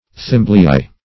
Thimbleeye \Thim"ble*eye`\, n. (Zool.)